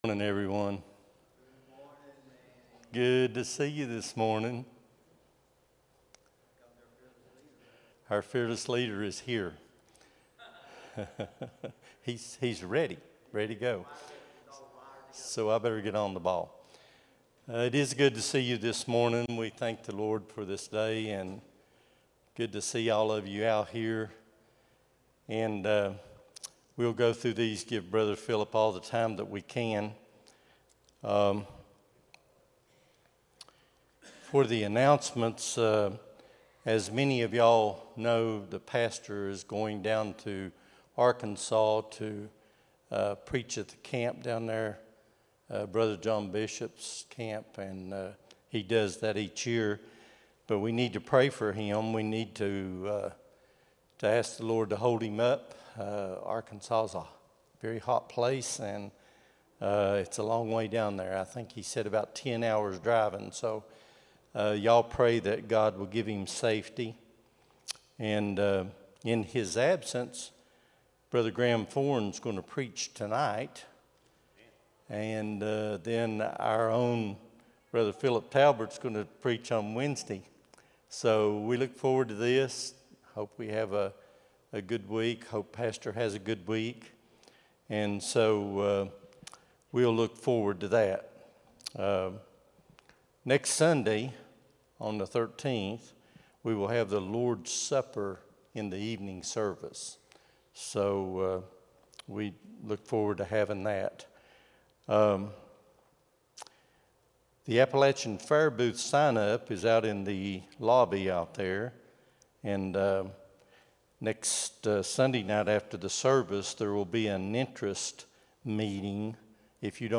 07-06-25 Sunday School | Buffalo Ridge Baptist Church